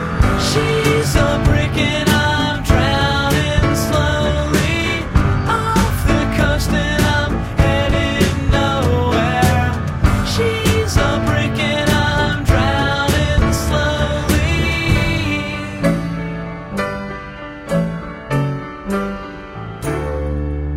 Genre Alternative Rock